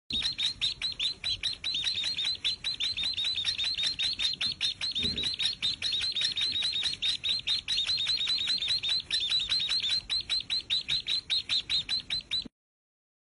十姐妹雏鸟叫声 白腰文鸟幼鸟叫声